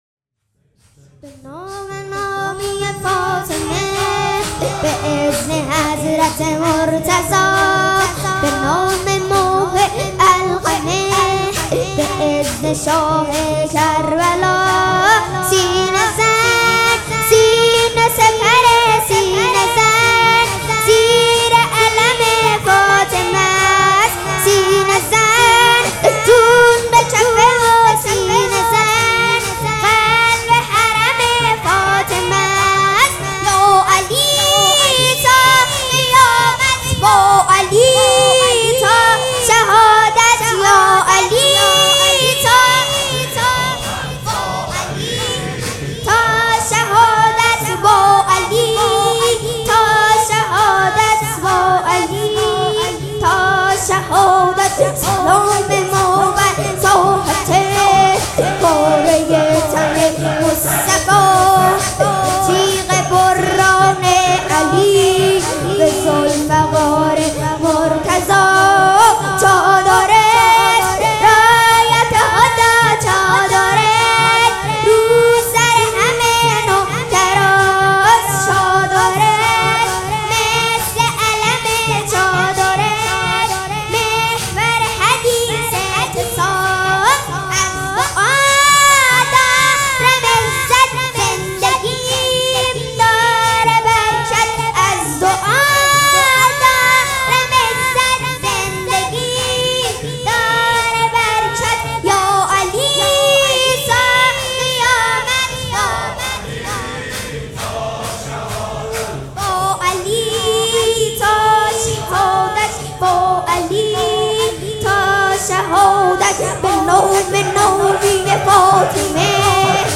مراسم عزاداری شب ششم فاطمیه ۱۴۴۳
سبک اثــر شور
مداح نوجوان